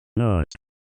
Nut-Button-Sound-Effect.mp3